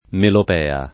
vai all'elenco alfabetico delle voci ingrandisci il carattere 100% rimpicciolisci il carattere stampa invia tramite posta elettronica codividi su Facebook melopea [ melop $ a ] (antiq. melopeia [ melop $L a ]) s. f. (mus.)